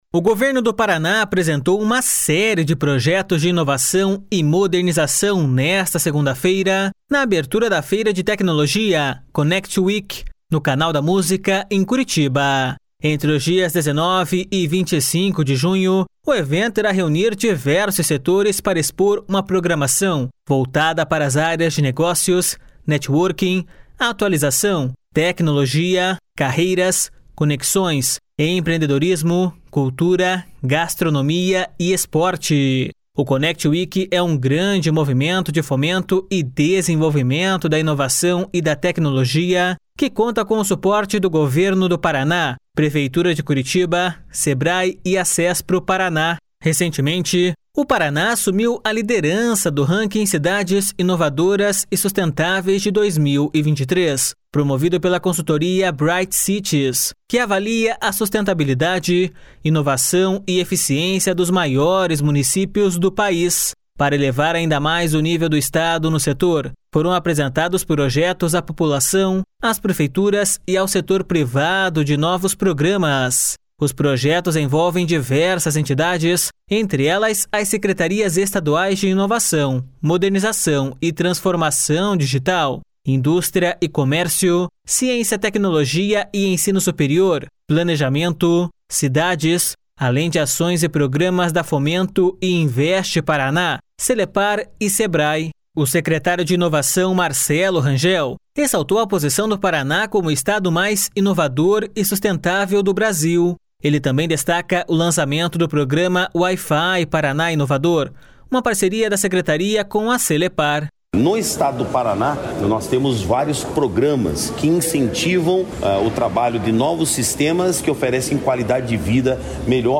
Ele também destaca o lançamento do programa Wi-fi Paraná Inovador, uma parceria da secretaria com a Celepar.// SONORA MARCELO RANGEL.//